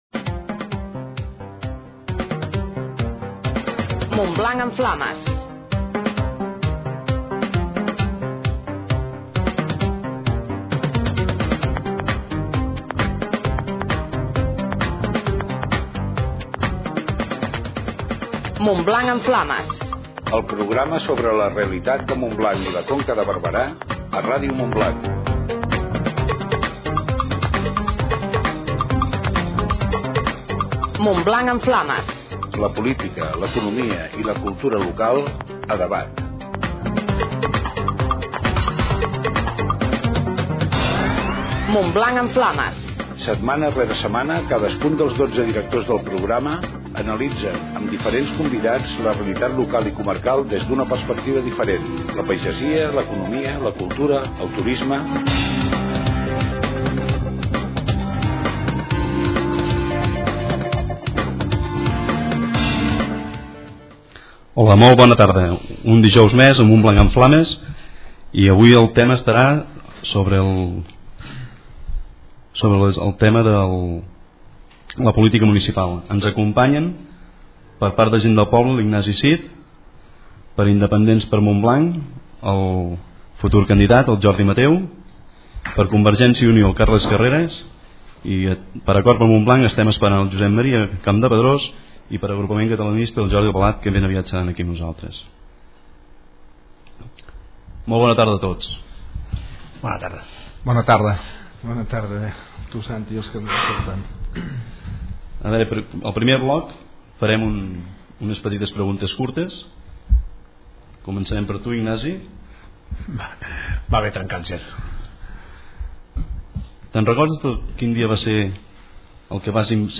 una interessant tertúlia
amb representants dels grups polítics municipals.